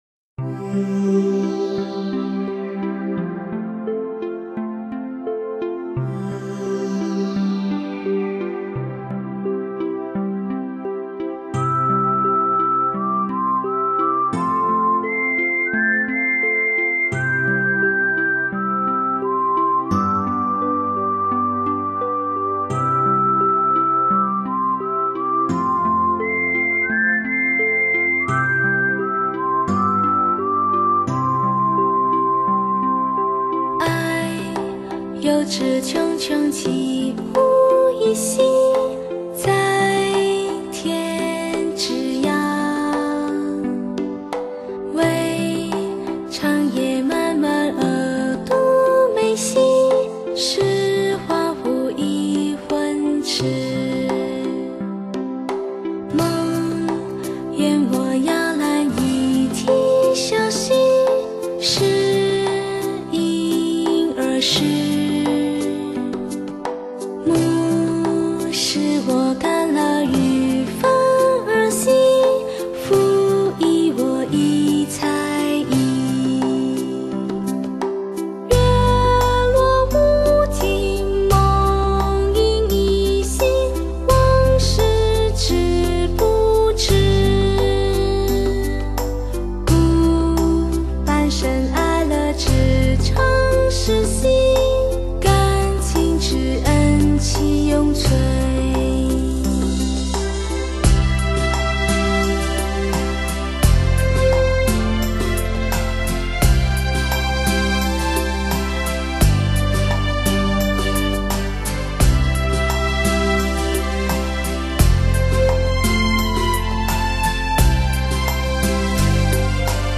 唯美的声音 最快乐的歌
清新唯美的声音带给人舒服静心的感受。